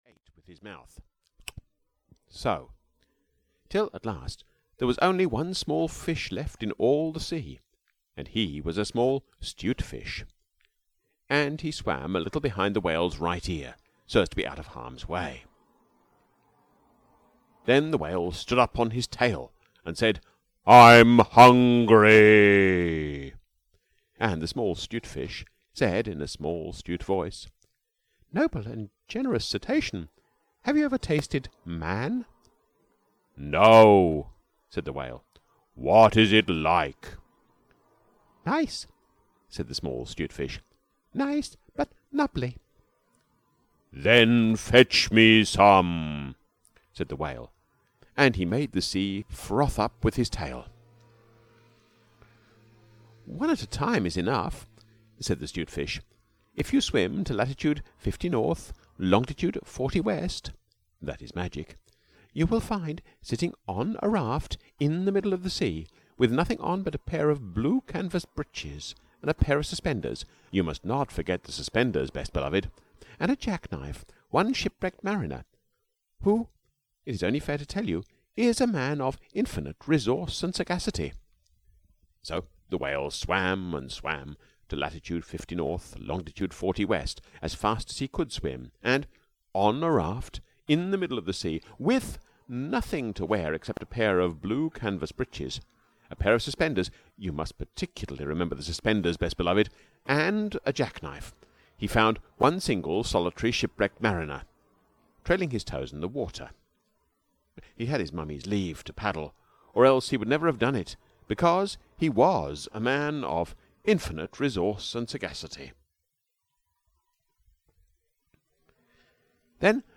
Just So Stories for Little Children (EN) audiokniha
Ukázka z knihy